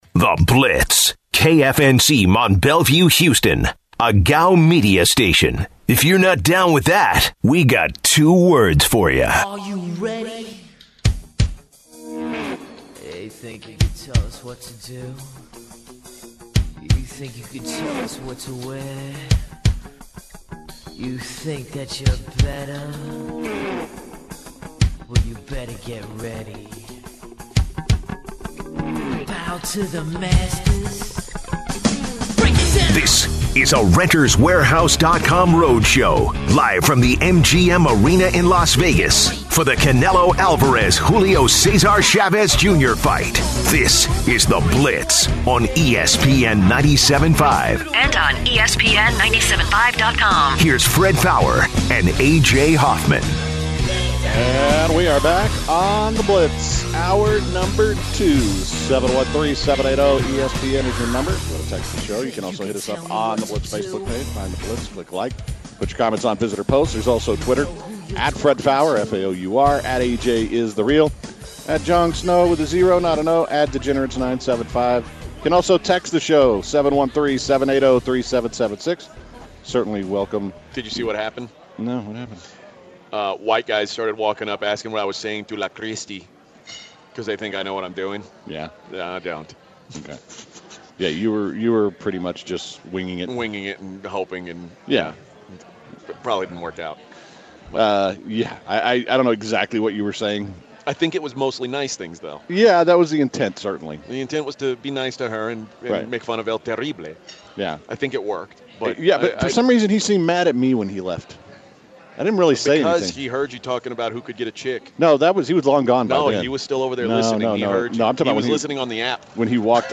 In the second hour, the guys kick off the show to talk about video games and pokemon. Kansas City Chief Head Coach Andy Reid reveals why he drafted Patrick Mahommes in the first round.